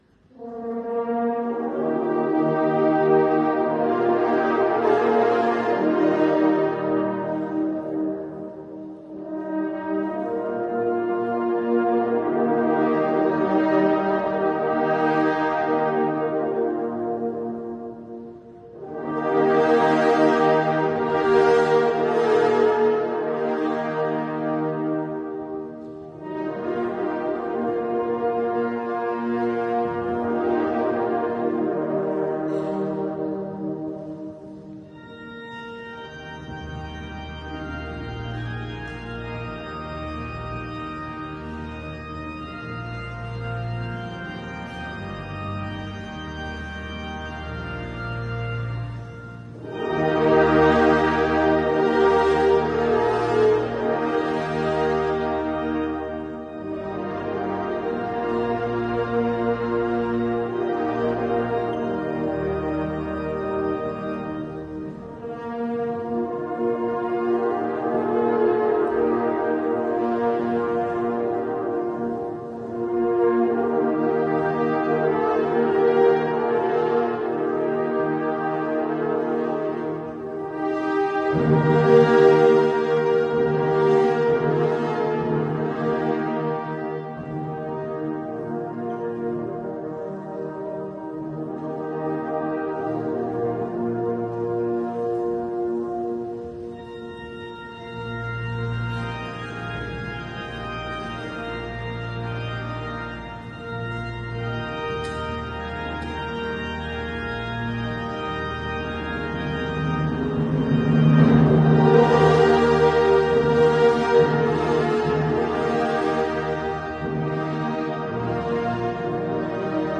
Besetzung: Instrumentalnoten für Horn